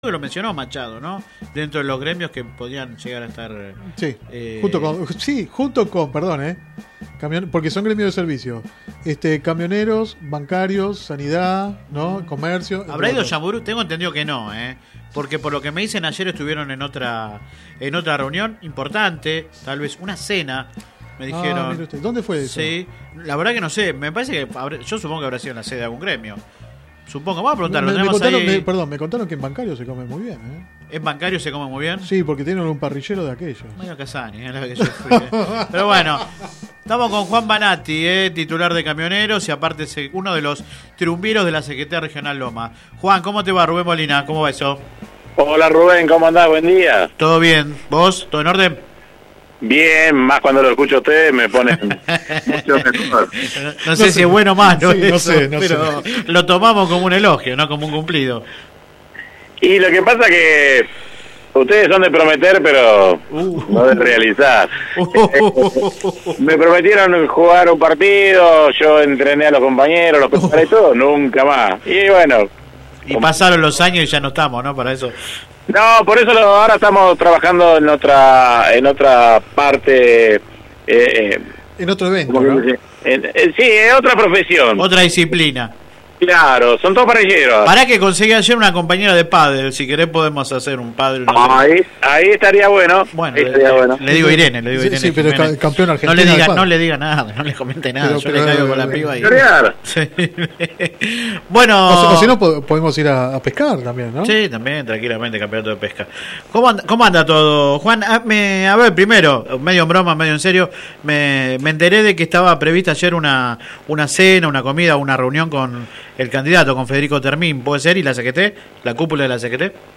Click acá entrevista radial https